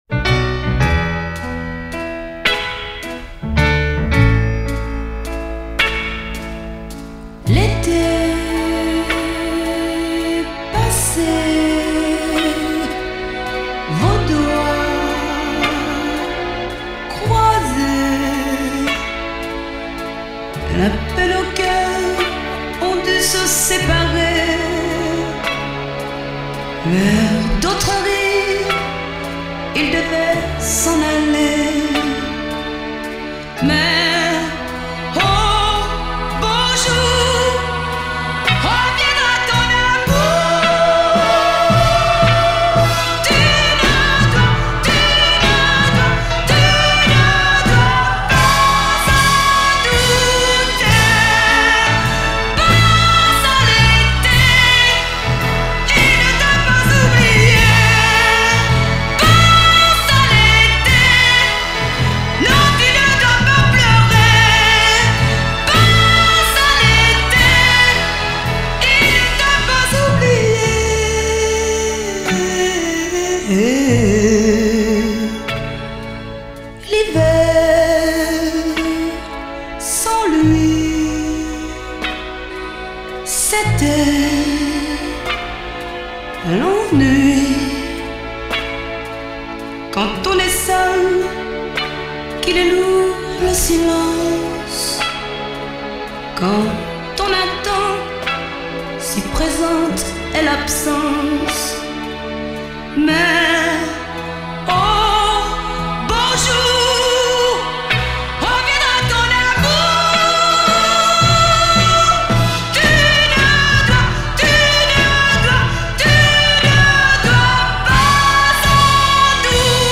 Suffice to say, she doesn’t sing songs passively.